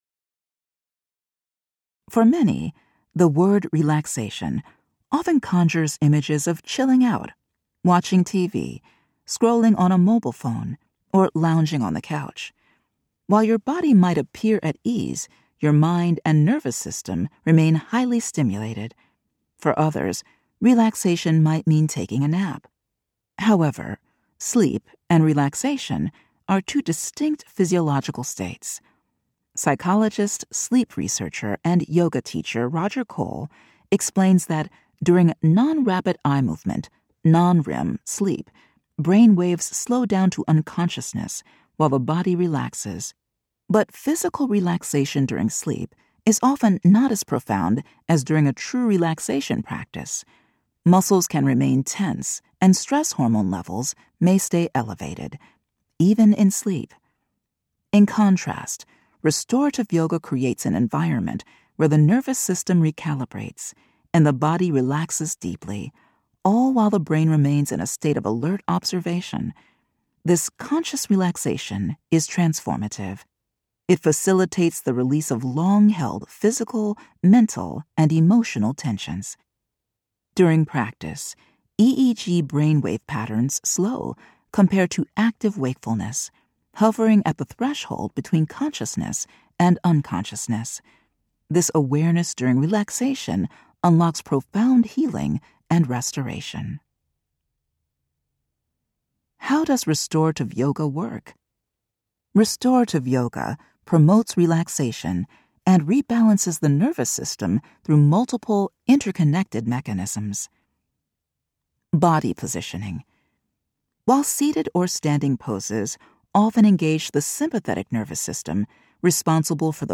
Now also available as an audiobook
The audiobook will be officially released on June 2, 2026, and a sample excerpt is now available to listen to and share.